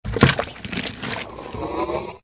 TL_quicksand.ogg